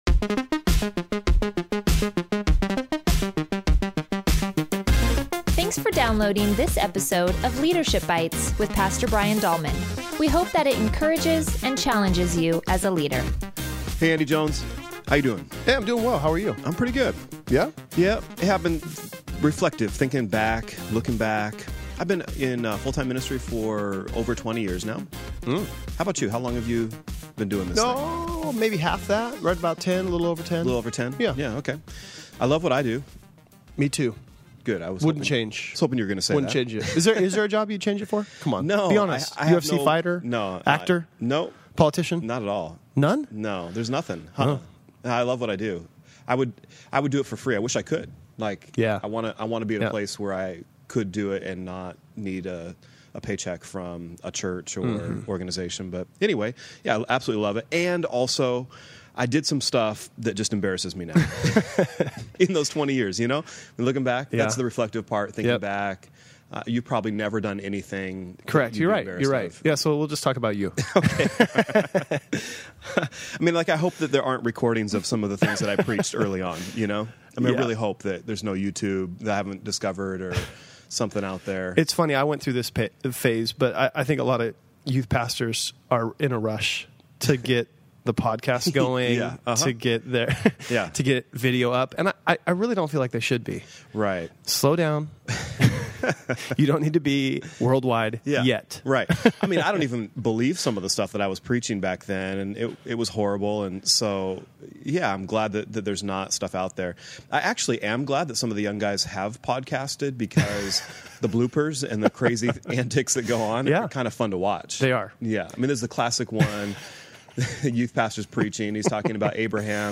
short leadership podcast